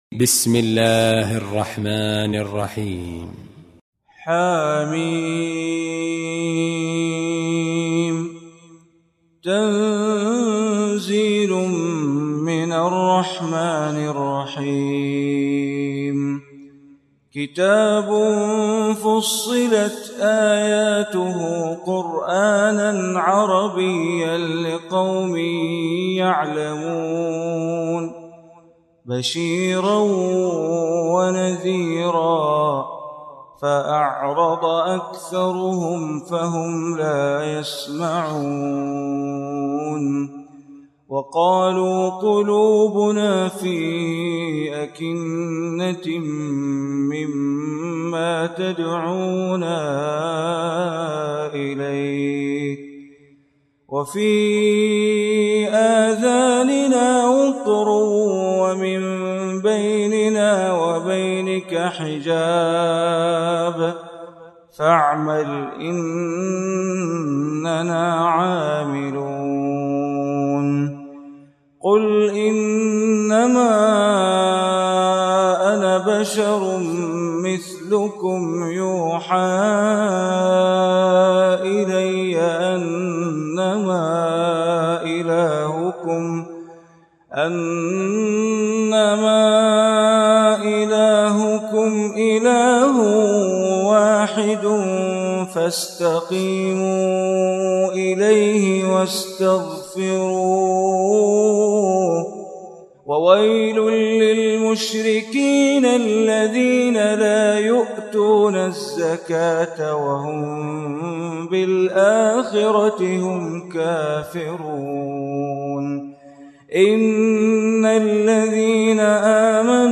Surah Fussilat Recitation by Sheikh Bandar Baleela
Surah Fussilat, listen online mp3 tilawat / recitation in Arabic recited by Imam e Kaaba Sheikh Bandar Baleela.